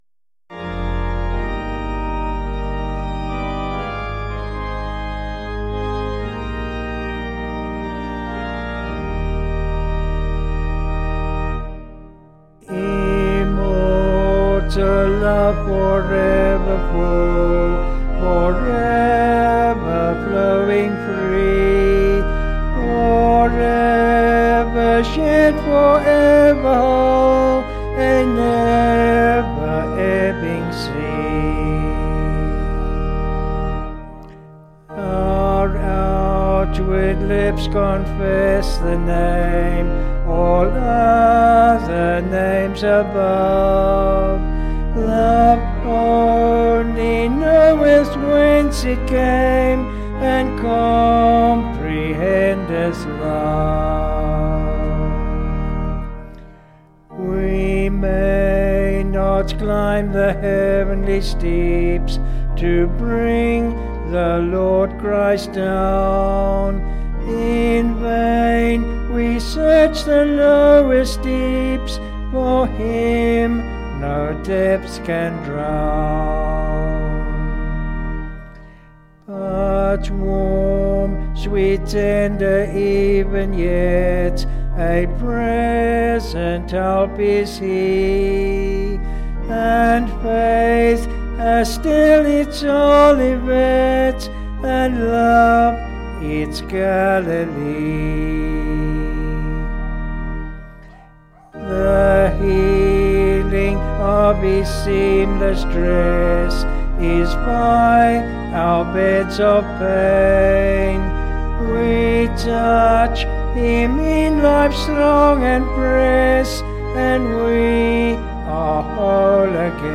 (BH)   7/Eb
Vocals and Organ   265.1kb Sung Lyrics